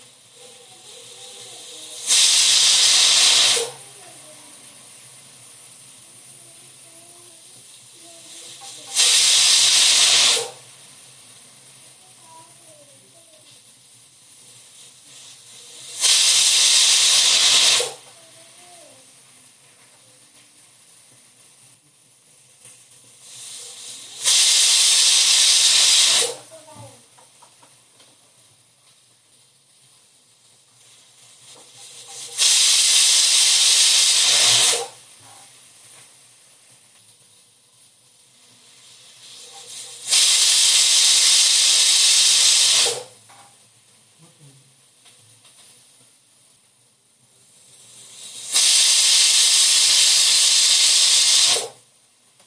Pressure Cooker Whistle Sound
Realistic pressure cooker whistle capturing the sharp steam release and high-pitched alert sound.
Category: Cooking · All Soundopedia recordings are 100% authentic — no AI, no synthesis.
pressure-cooker-whistle-sound